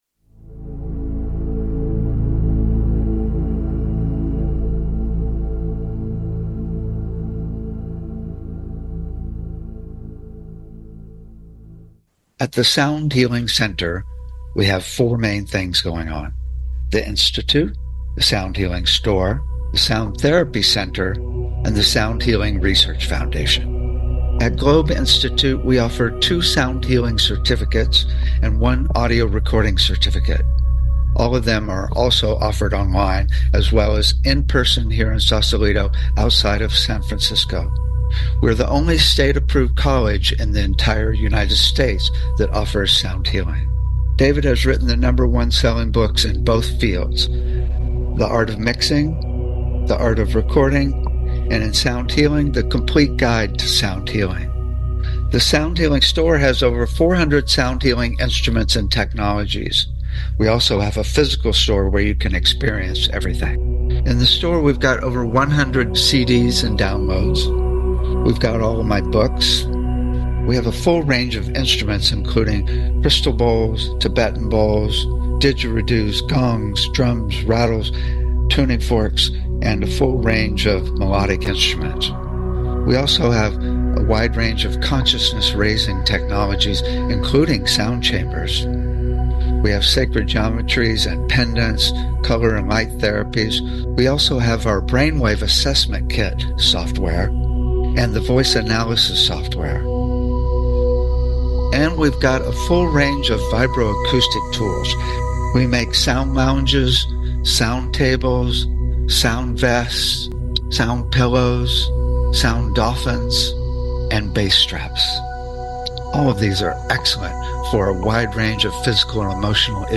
Talk Show Episode, Audio Podcast, Sound Healing and Activation vs Calming and Peace on , show guests , about Activation vs Calming and Peace, categorized as Education,Energy Healing,Sound Healing,Love & Relationships,Emotional Health and Freedom,Mental Health,Science,Self Help,Spiritual